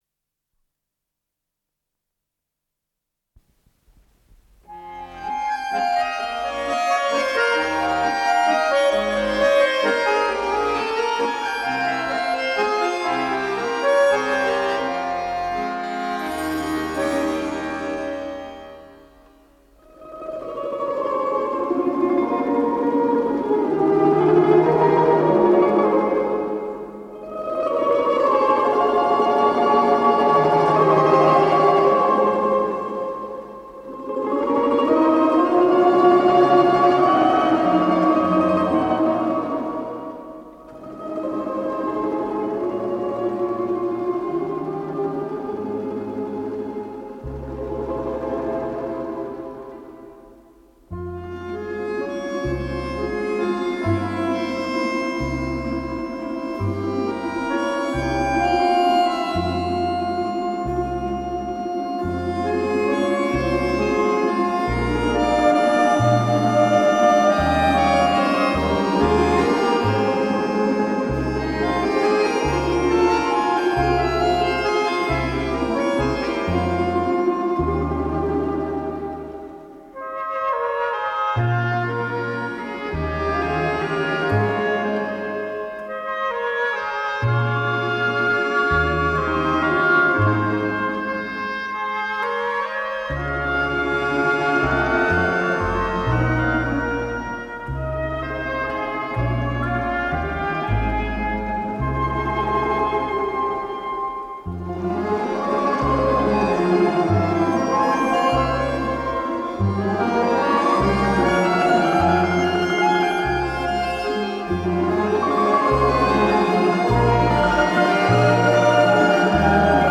Запись 1986 год Стерео дубль.